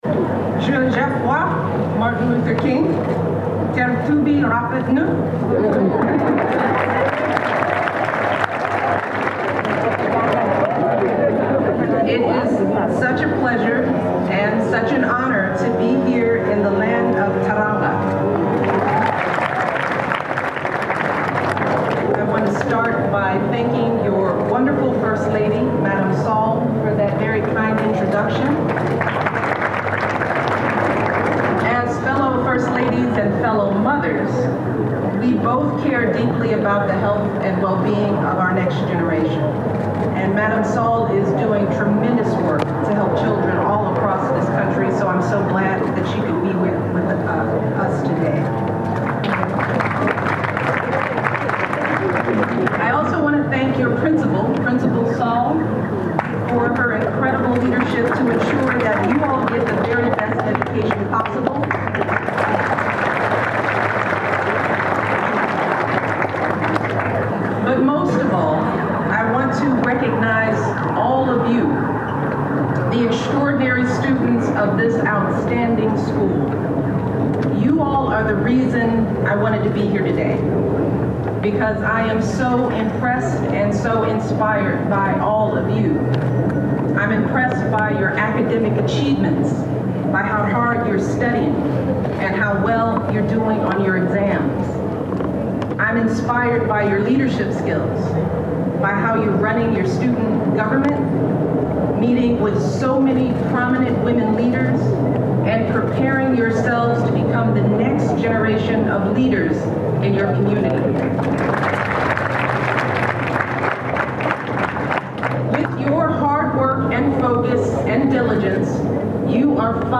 Mrs Michelle Obama's speech in Senegal